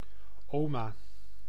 Ääntäminen
IPA: /ˈoːma/